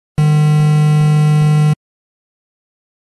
Звук как в приставках Денди и Сега